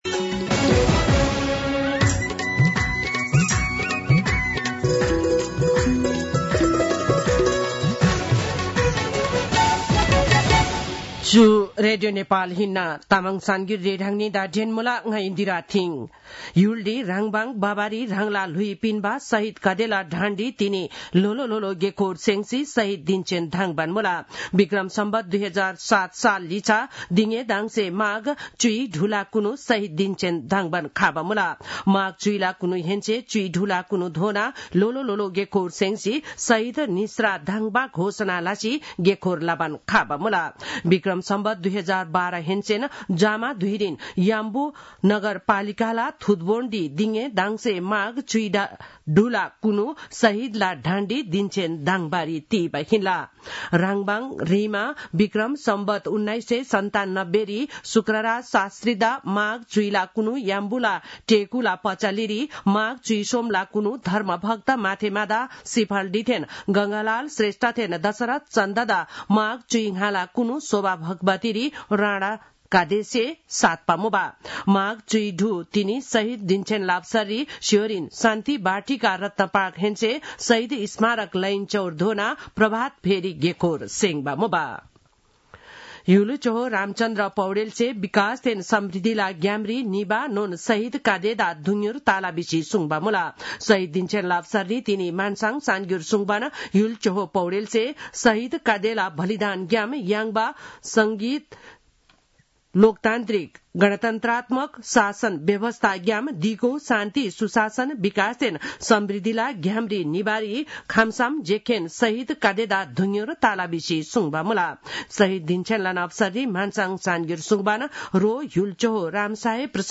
तामाङ भाषाको समाचार : १७ माघ , २०८१